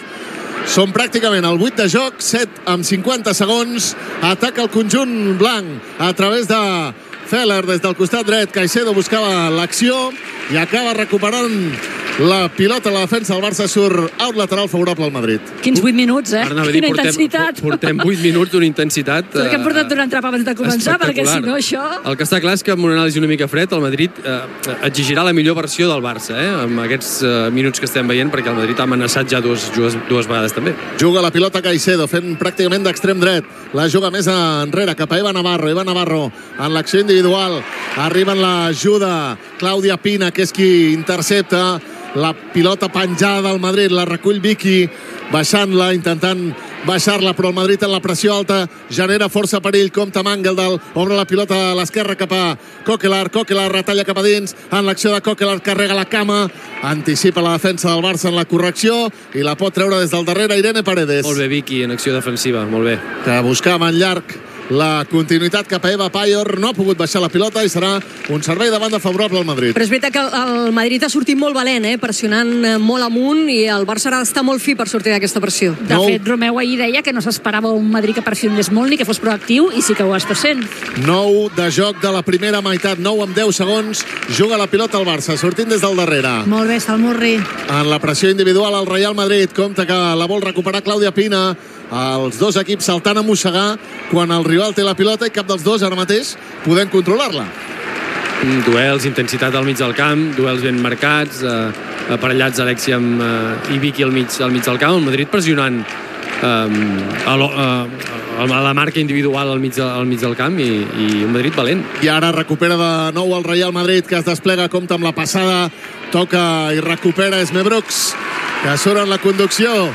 6518b9119edf282b636f22aa4e88fc013fd4198c.mp3 Títol Catalunya Ràdio - La TdG Emissora Catalunya Ràdio Cadena Catalunya Ràdio Titularitat Pública nacional Nom programa La TdG Descripció Transmissió de la primera part del partit de futbol femení entre el Futbol Club Barcelona i el Real Madrid des de l'Estadi Lluís Companys de Barcelona.
Gènere radiofònic Esportiu